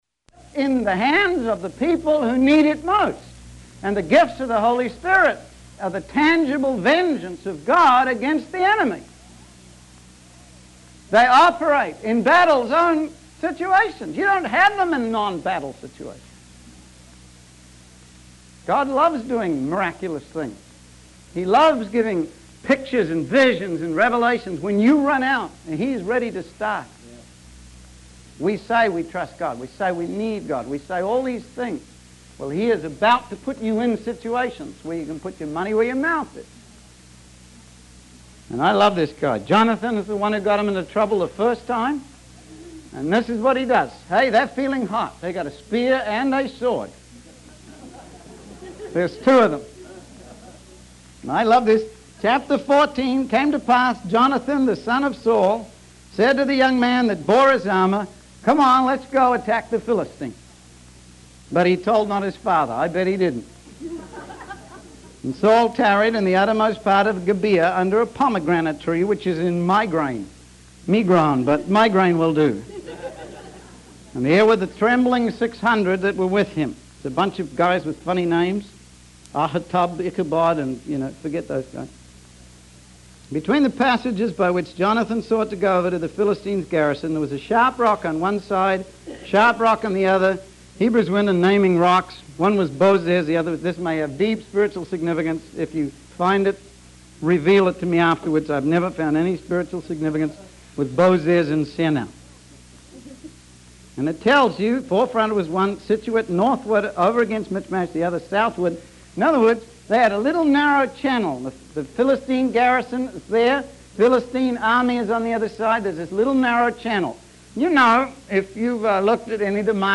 In this sermon, the preacher emphasizes the importance of trusting in God and putting our faith into action. He uses the story of Jonathan and his armor bearer to illustrate this point.